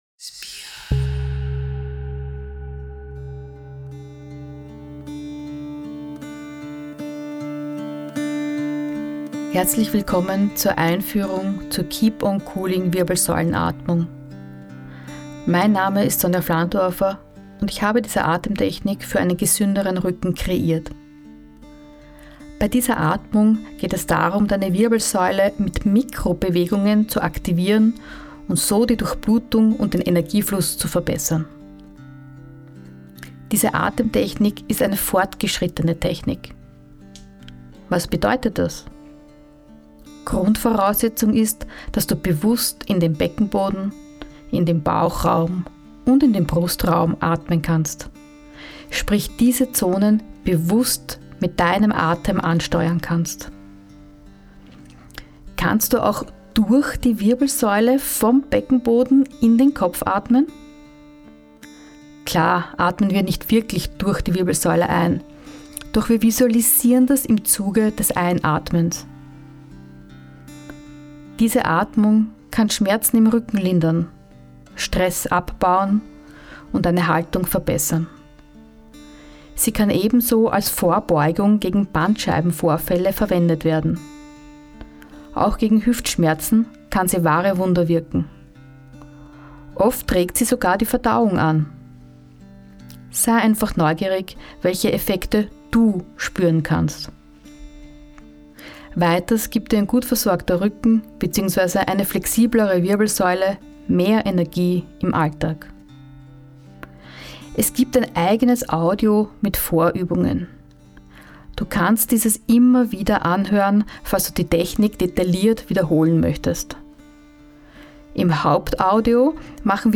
Zwei Audios mit Vorübungen zum Erlernen und Vertiefen der Technik und einer Audioanleitung mit der Keep on cooling-Wirbelsäulenatmung